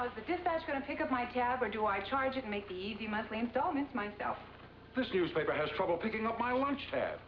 Memorable Dialog